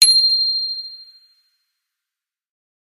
bicycle-bell_14
bell bicycle bike clang contact ding glock glockenspiel sound effect free sound royalty free Sound Effects